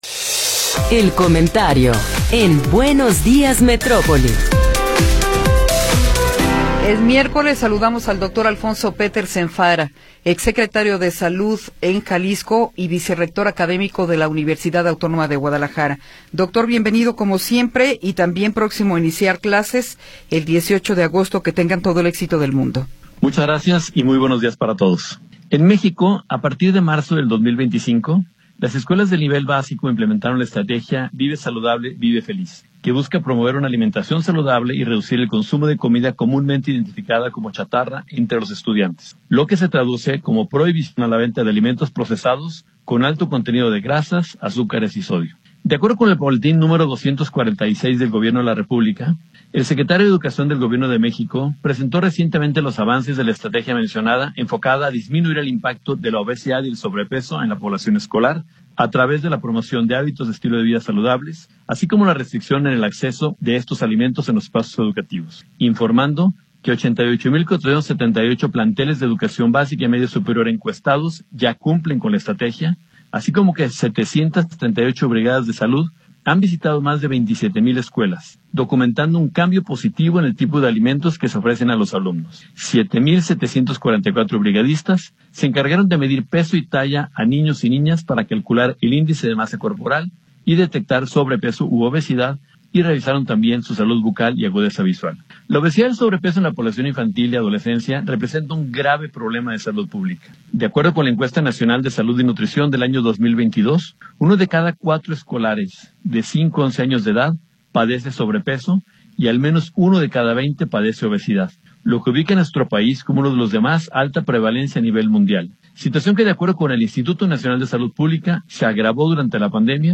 Comentario de Alfonso Petersen Farah